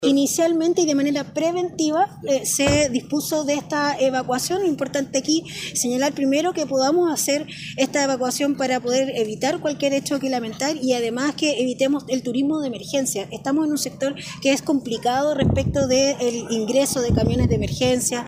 Por su parte, la delegada presidencial regional, Sofía González, dijo que la evacuación se realizó de forma preventiva; y realizó un llamado a evitar el turismo “de emergencia”.